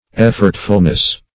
effortfulness - definition of effortfulness - synonyms, pronunciation, spelling from Free Dictionary